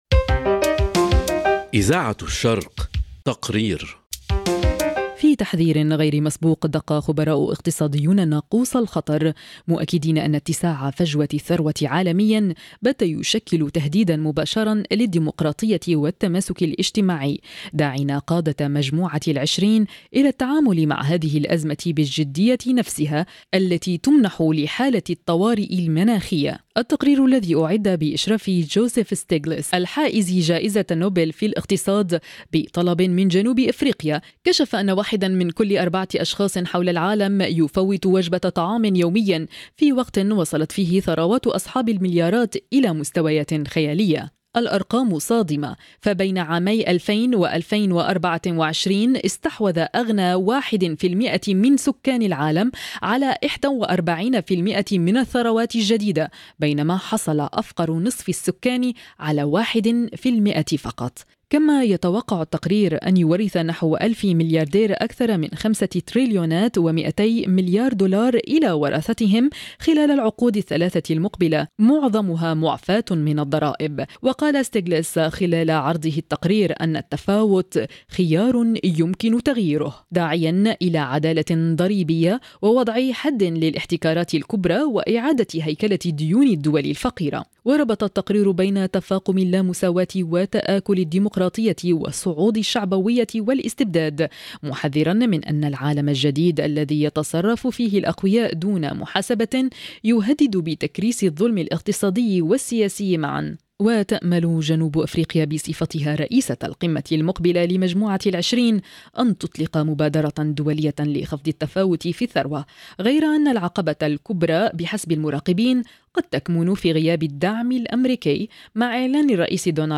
تقرير: التفاوت في الثروة، خطر جديد يهدد الديمقراطية عالميًا